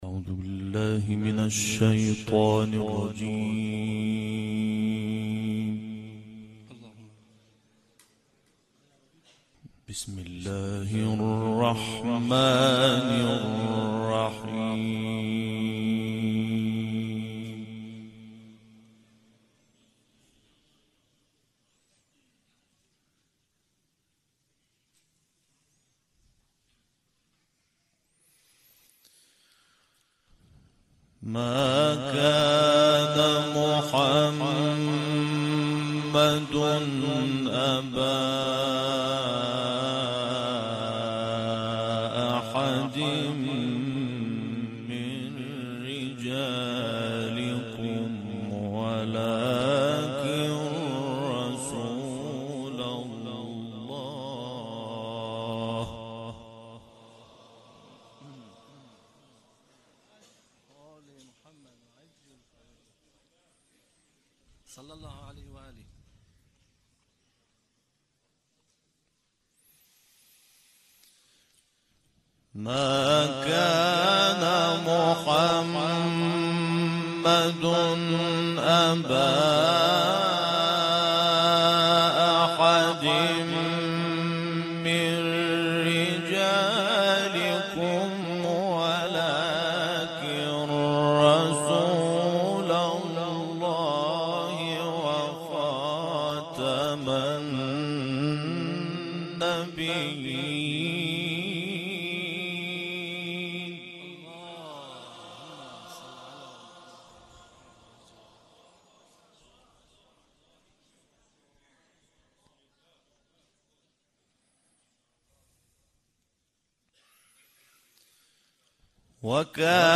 به مناسبت هفته وحدت و سالروز میلاد پیامبر مهربانی، تلاوت آیات 40 تا ۴۸ سوره مبارکه احزاب را با صدای محمود شحات انور، قاری برجسته مصری می‌شنوید. این تلاوت در بهمن 1391 در شهر مقدس مشهد اجرا شده است.